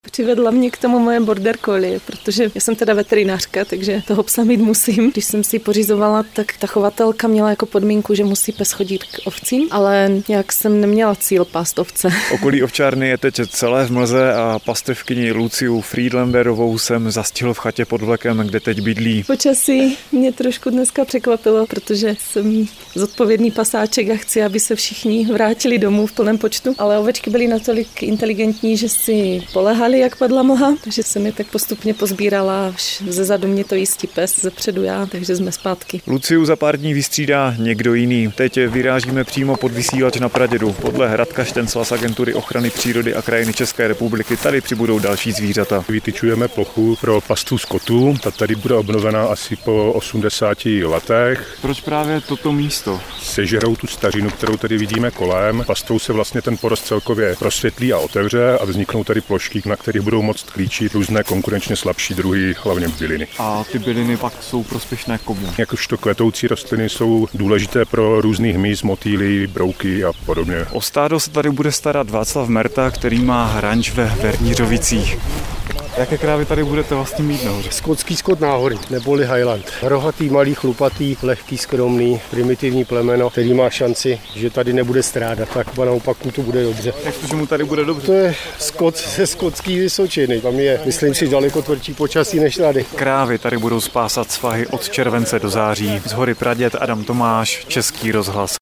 Debata: ANO 2011, REFERENDUM - Hlas Lidu, Demokratická strana zelených - ZA PRÁVA ZVÍŘAT - 19.09.2024